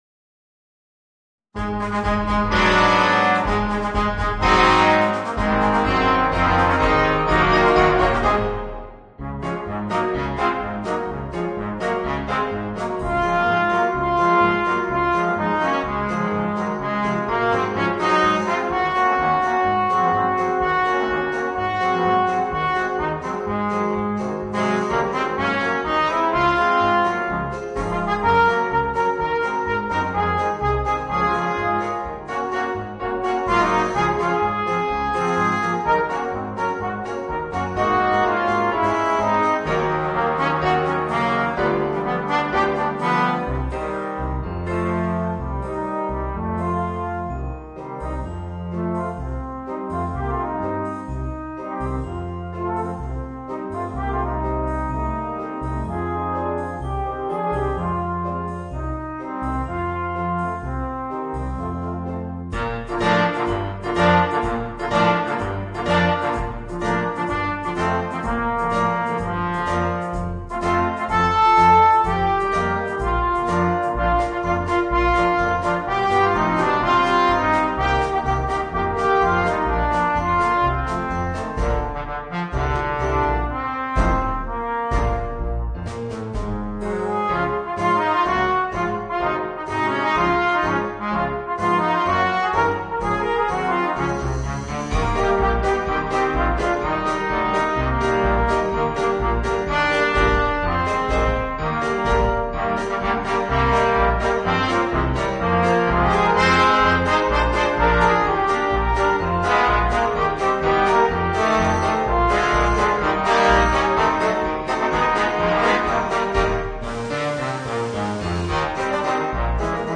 Voicing: 4 Trombones